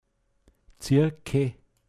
pinzgauer mundart